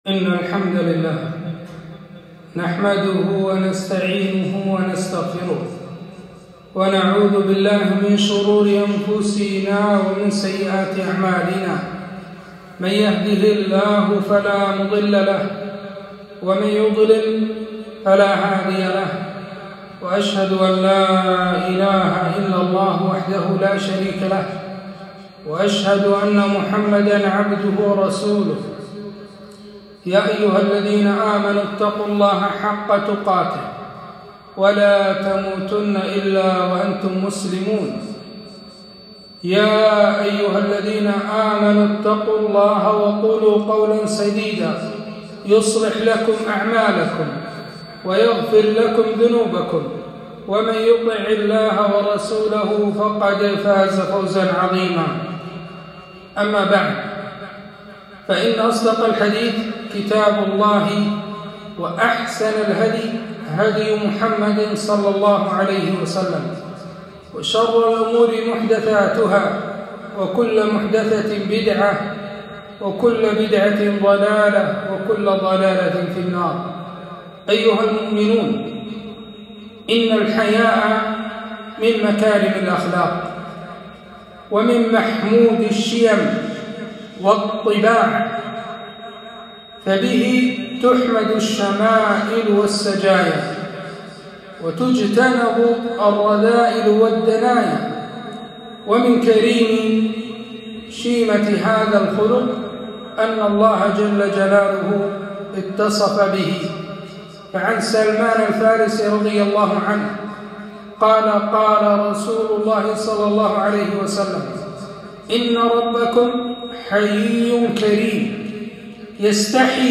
خطبة - أهمية الحياء في الإسلام